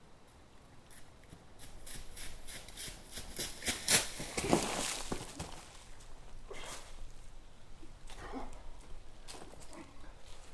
Звук мужчины бегущего по траве с последующим спотыканием и падением